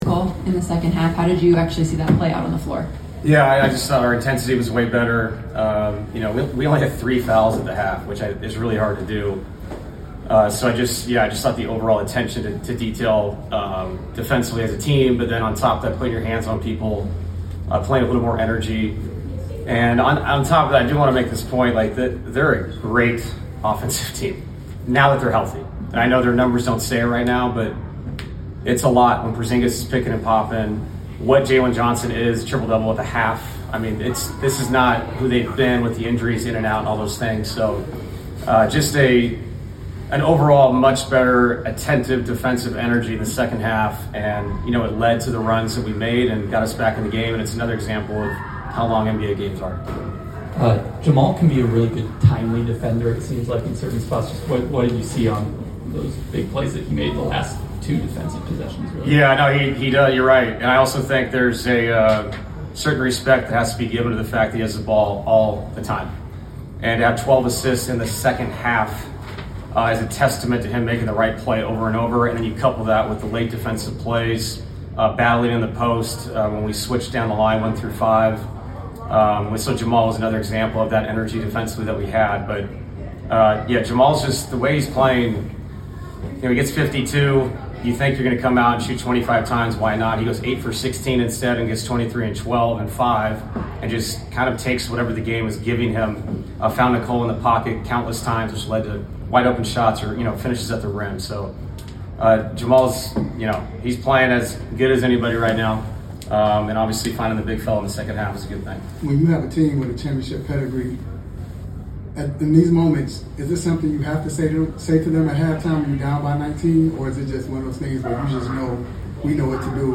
Denver Nuggets Coach David Adelman Postgame Interview after defeating the Atlanta Hawks at State Farm Arena.